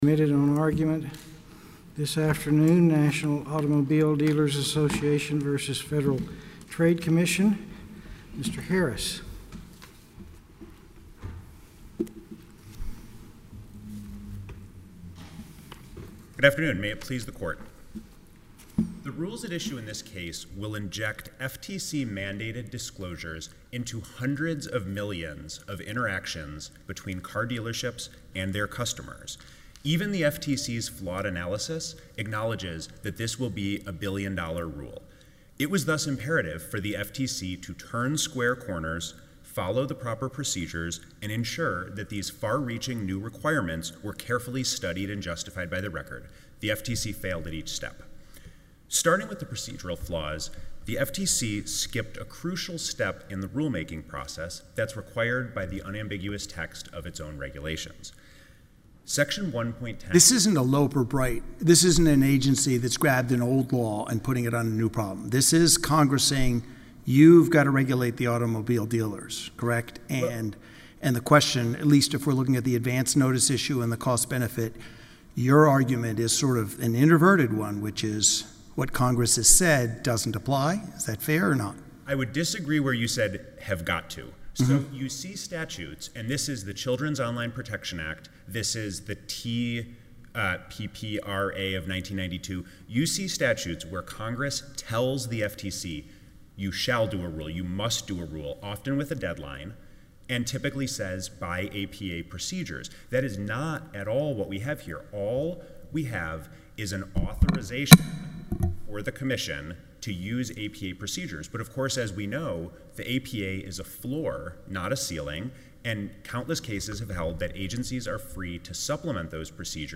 In case you missed it -- the Center's amicus brief on behalf of economists was along for the ride at the Fifth Circuit Court of Appeals last week. At the oral arguments in National Association of Automobile Dealers v. FTC, the judges and advocates repeatedly referenced our brief, which lent an independent economic analysis in support of the FTC's Combatting Auto Retail Scams (CARS) Rule protecting consumers from deceptive pricing in the car buying process.